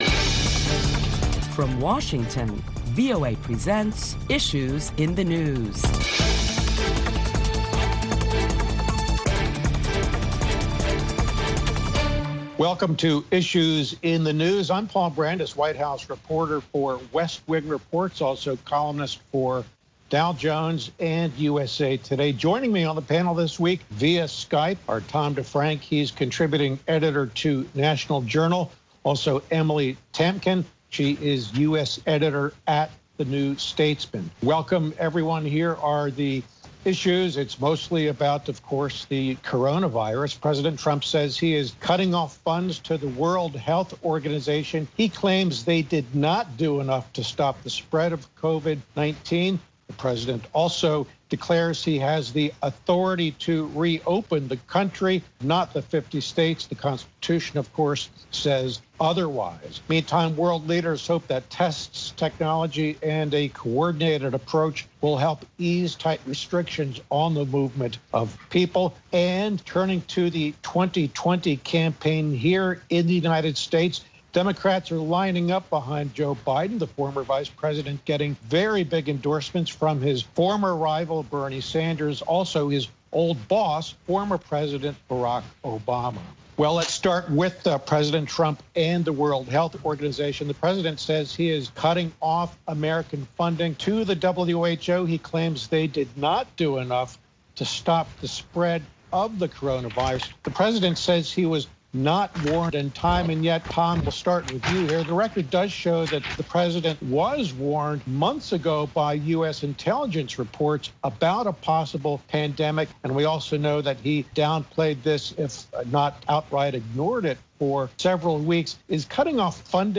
Listen to a panel of prominent Washington journalists as they deliberate the latest top stories of the week that include President Trump suspending funds to the WHO.